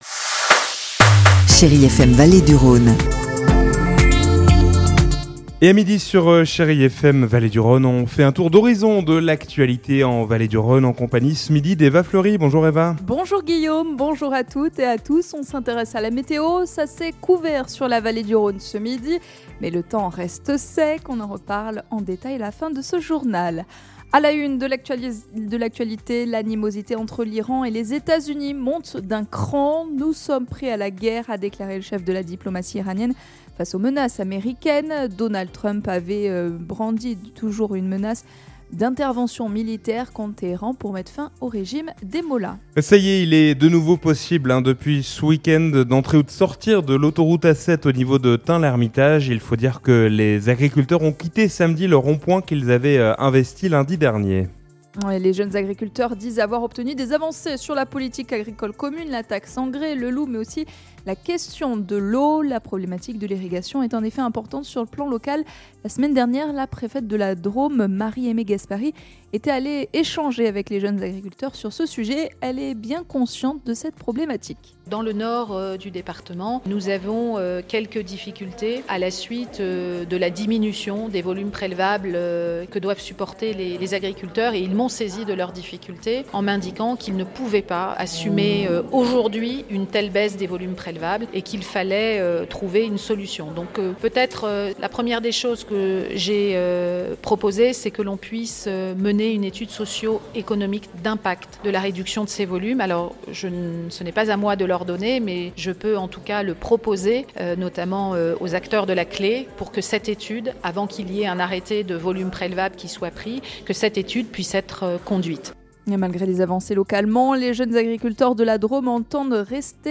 Lundi 12 janvier : Le journal de 12h